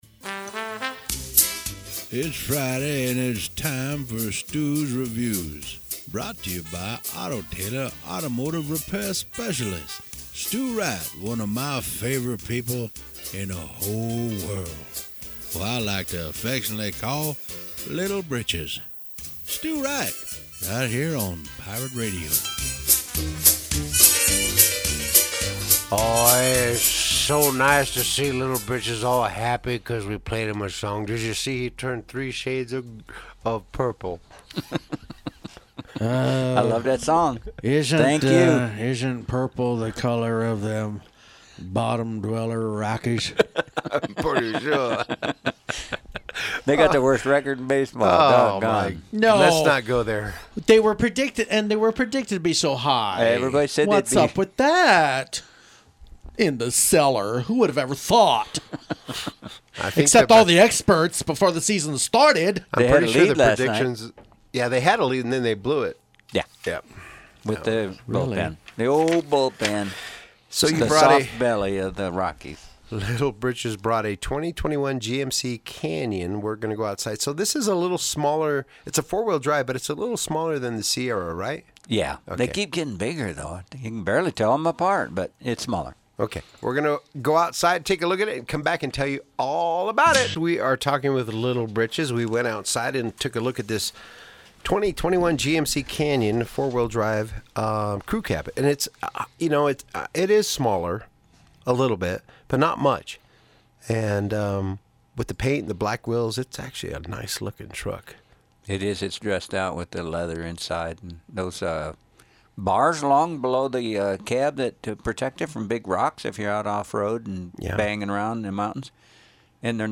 Radio review down at Pirate Radio studios